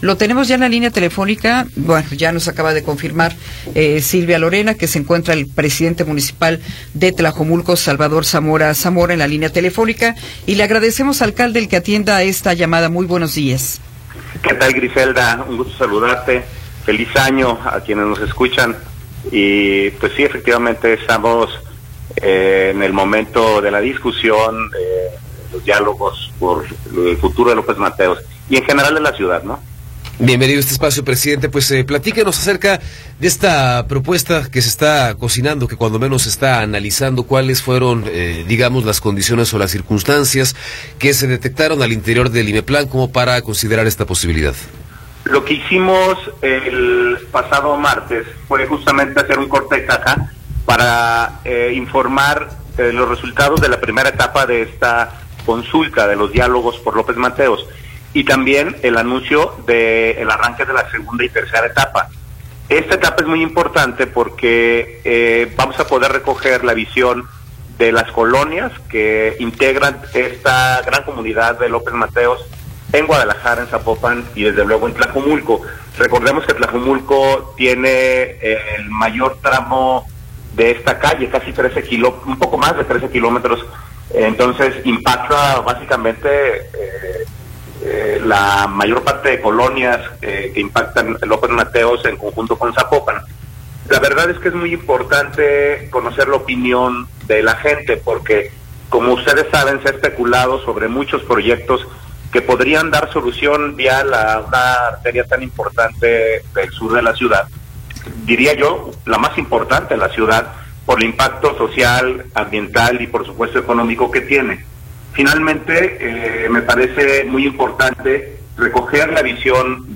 Entrevista con Salvador Zamora Zamora
Salvador Zamora Zamora, presidente municipal de Tlajomulco, nos habla sobre las posibles soluciones para la problemática de movilidad en la avenida López Mateos.